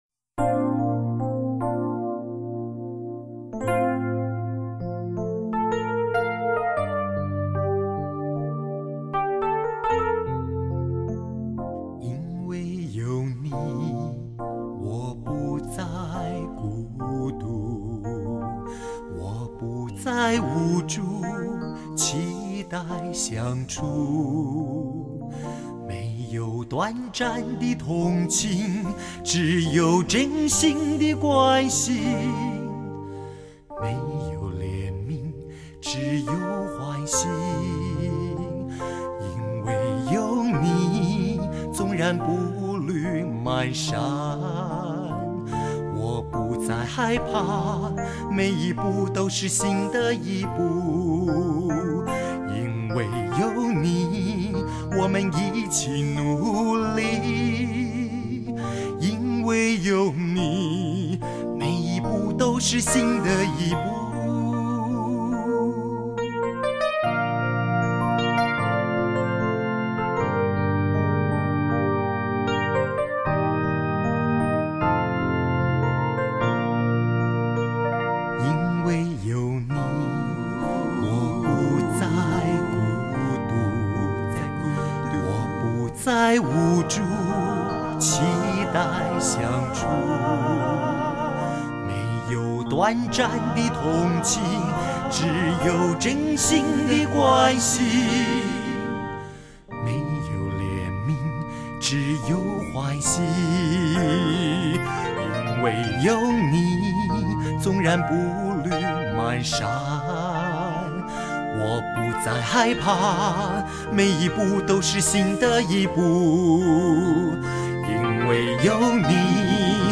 qi-e-hui-ge-nan-sheng-ban.mp3